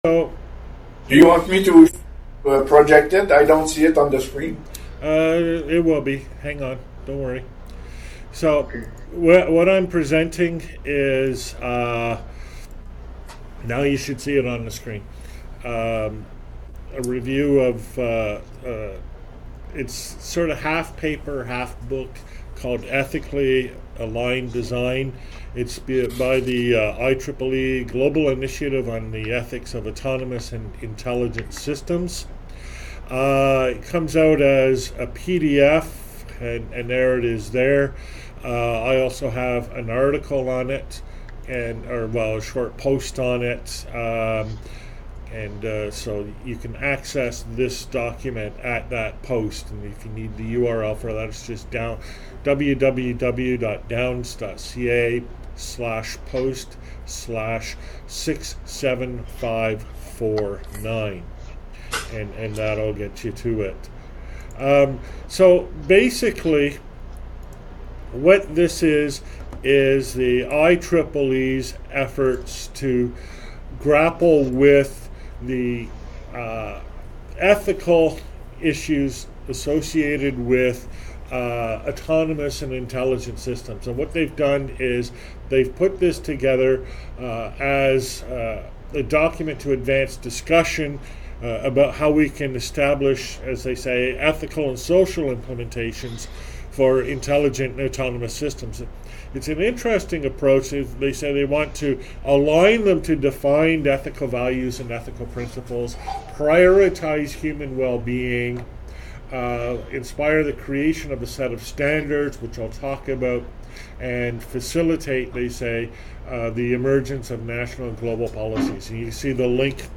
Review of Ethically Aligned Design Previous Next Page: / Author: Downloads: (Old style) [ Slides ] [ PDF ] [ Audio ] [ Video ] [] HCT Reading Club, National Research Council, Ottawa, via Jabber, Seminar, Mar 13, 2018. In this presentation I review Ethically Aligned Design by The IEEE Global Initiative on Ethics of Autonomous and Intelligent Systems, focusing on the section on classical ethics in autonomous and intelligent systems (A/IS).